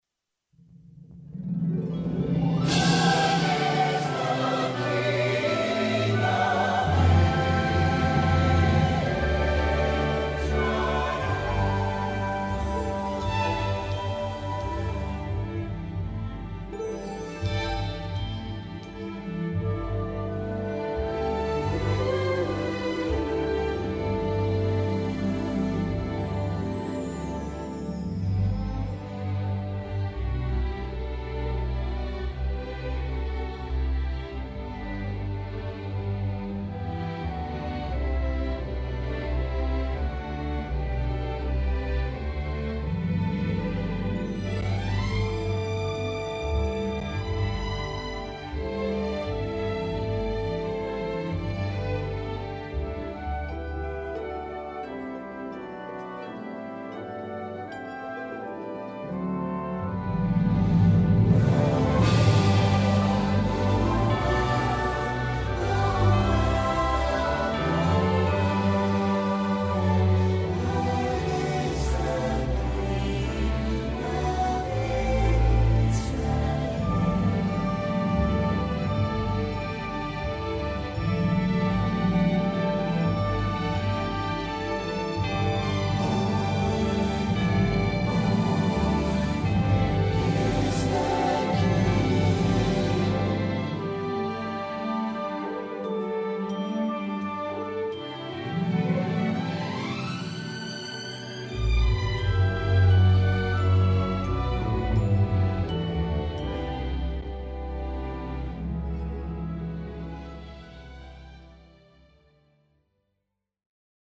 Medium Key with BV